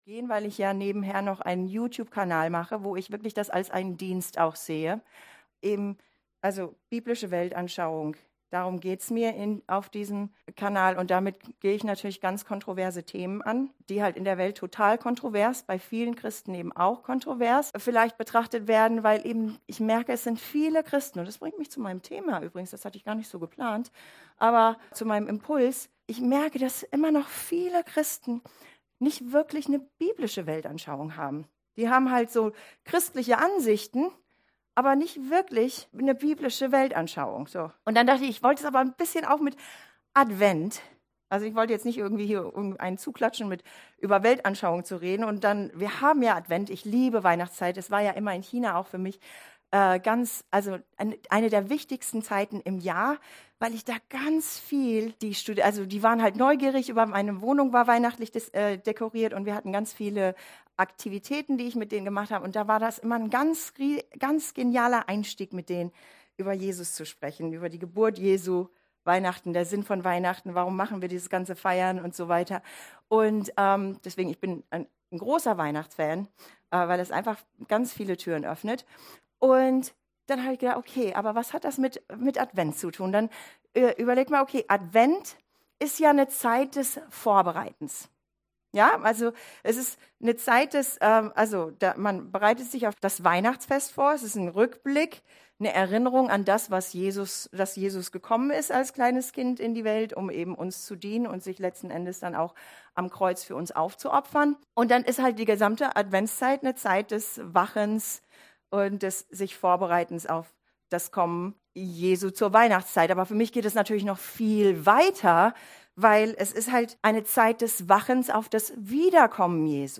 PREDIGTEN zum Nachhören als Downloadliste – FEZ